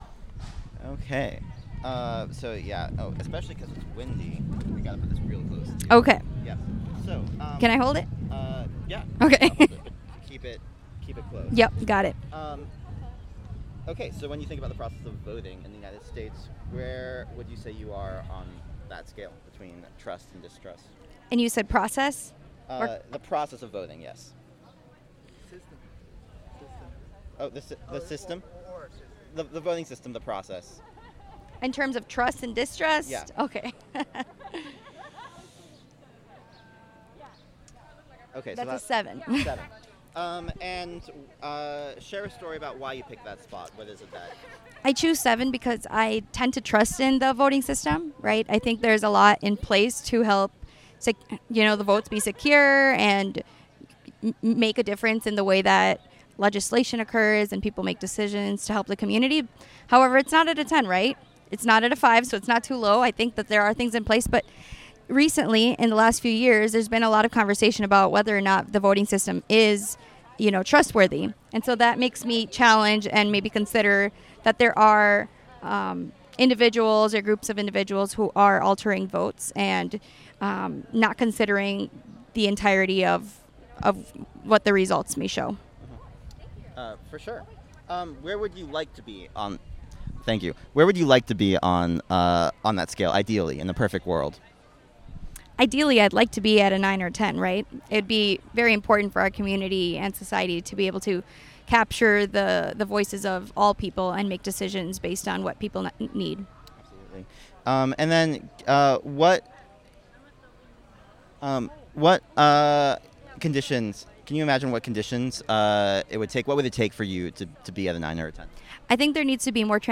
Interview
Location Despensa de la Paz